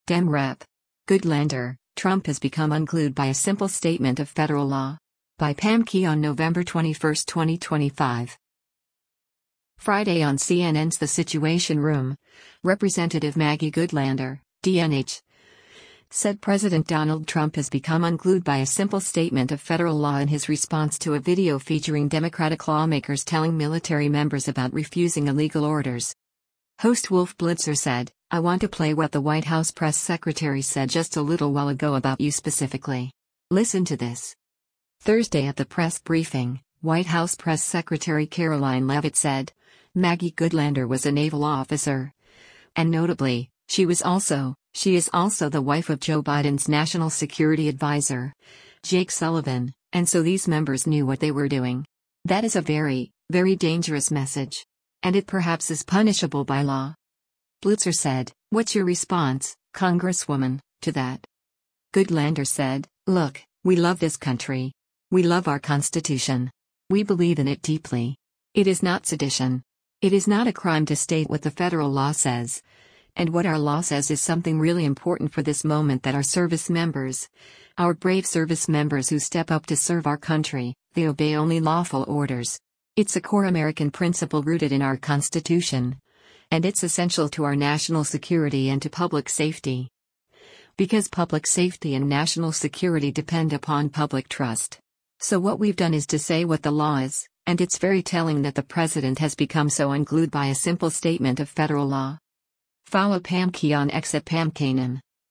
Friday on CNN’s “The Situation Room,” Rep. Maggie Goodlander (D-NH) said President Donald Trump has become “unglued by a simple statement of federal law” in his response to a video featuring Democratic lawmakers telling military members about refusing illegal orders.